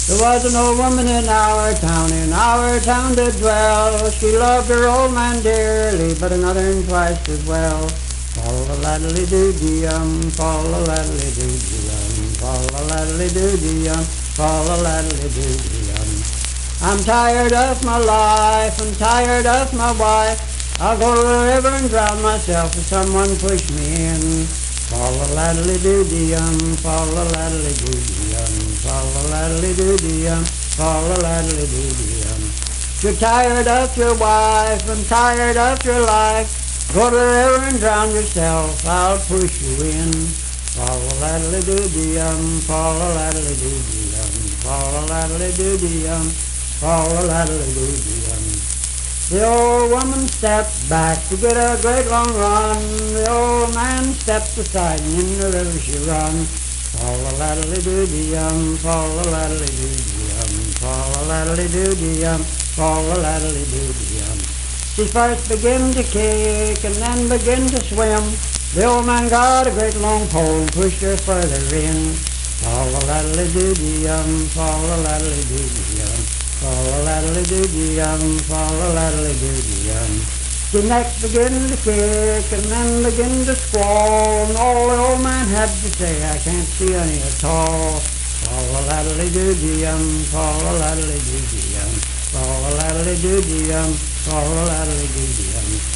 Unaccompanied vocal music
Performed in Oak Point, WV.
Voice (sung)